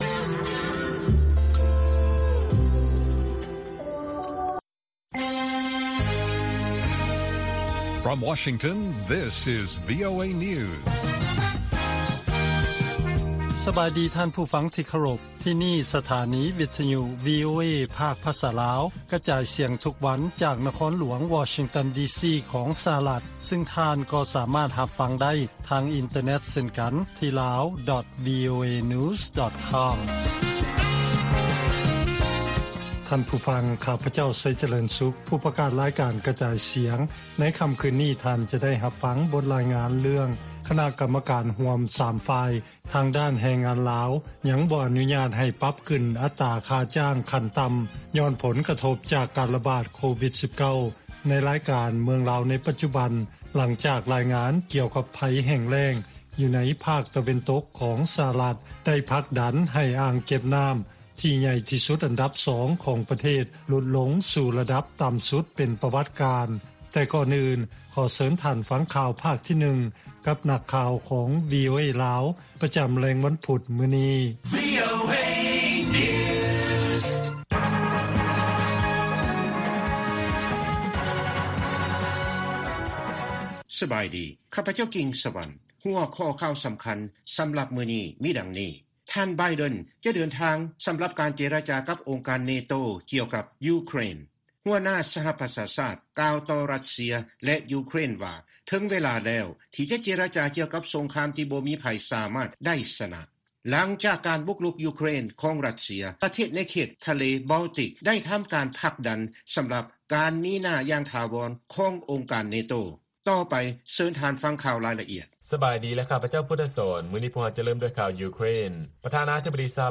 ລາຍການກະຈາຍສຽງຂອງວີໂອເອ ລາວ: ທ່ານ ໄບເດັນ ຈະເດີນທາງ ສຳລັບການເຈລະຈາກັບອົງການ NATO ກ່ຽວກັບ ຢູເຄຣນ